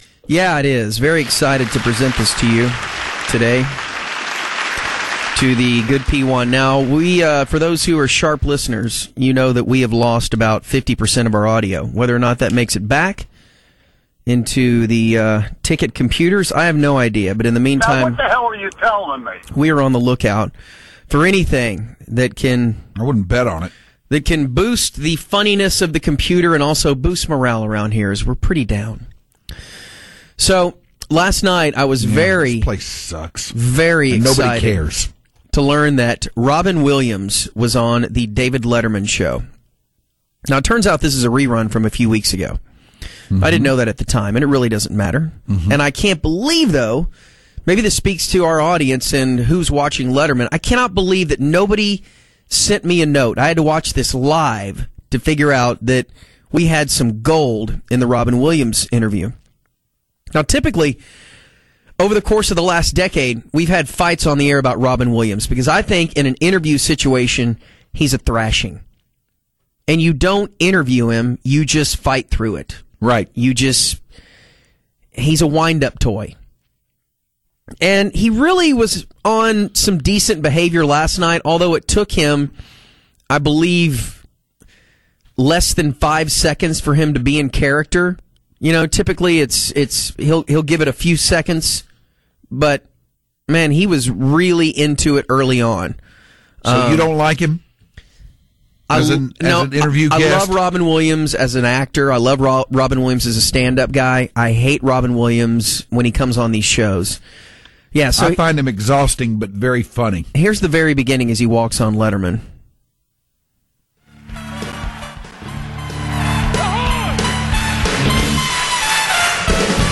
It’s a fart drop that we will continue to hear for years to come … well until they move again and engineering loses it.
fun-with-fart-audio.mp3